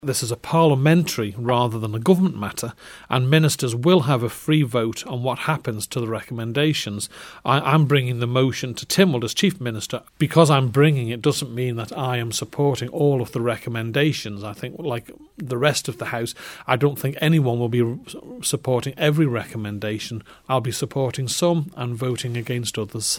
Chief Minister Howard Quayle says he's allowing ministers a free vote on the issues raised in the report: